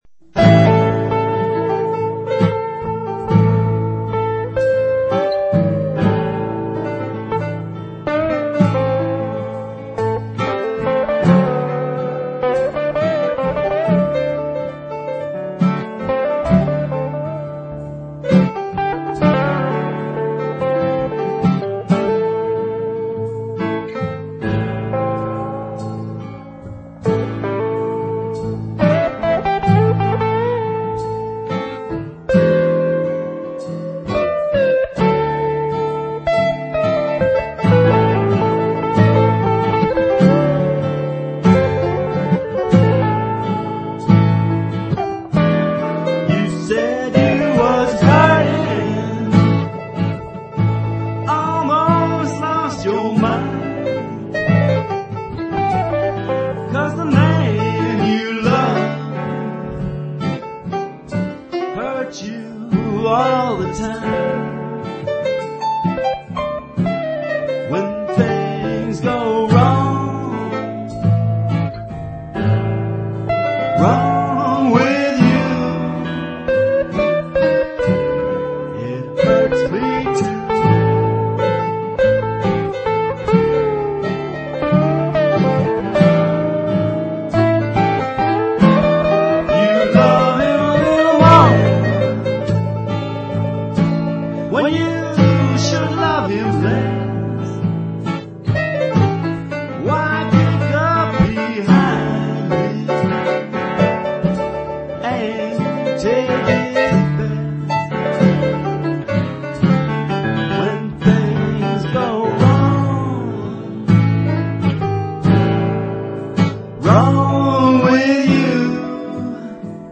Blues:
I wanted to record a regular blues and chose this one.